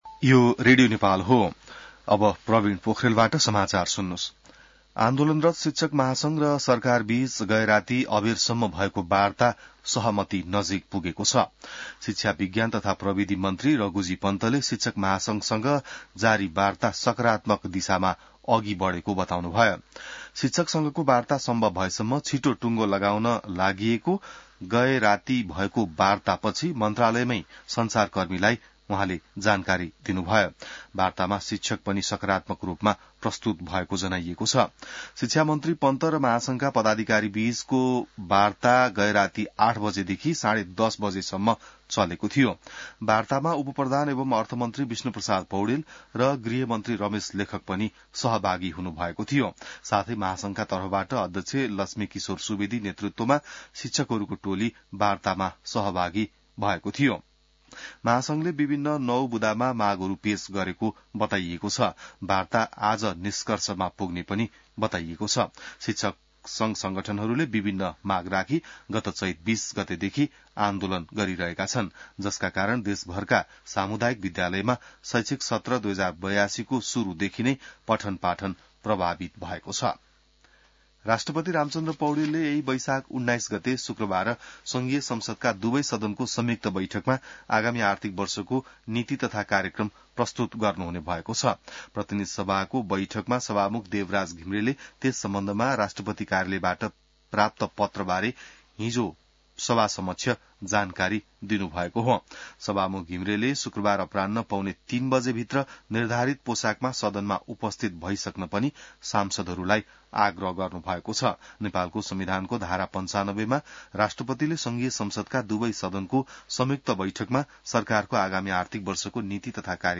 An online outlet of Nepal's national radio broadcaster
बिहान ६ बजेको नेपाली समाचार : १७ वैशाख , २०८२